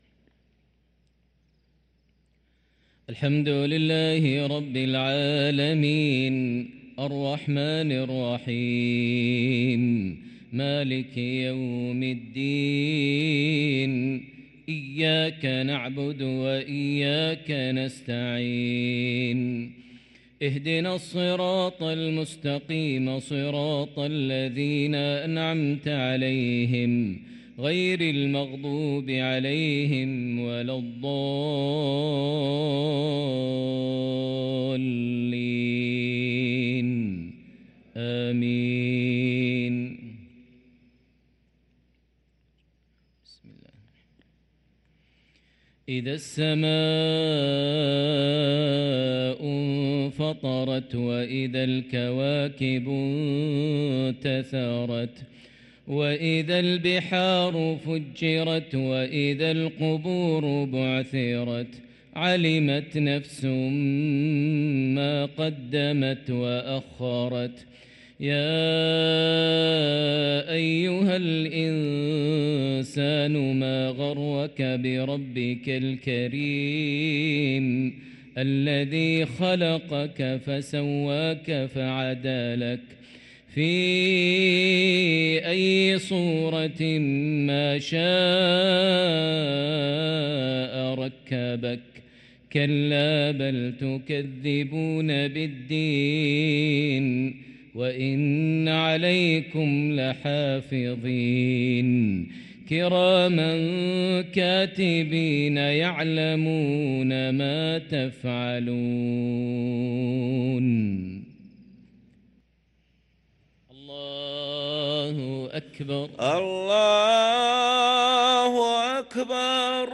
صلاة المغرب للقارئ ماهر المعيقلي 1 ربيع الآخر 1445 هـ
تِلَاوَات الْحَرَمَيْن .